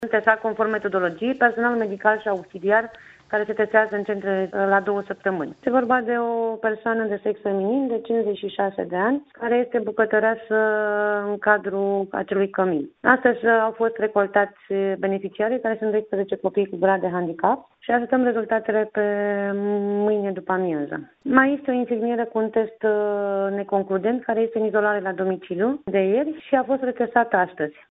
În total au fost testaţi pentru a se vedea dacă au contractat noul coronavirus, 12 beneficiari şi şase angajaţi, după cum a declarat Directorul DSP Botoşani, Monica Adăscăliţei: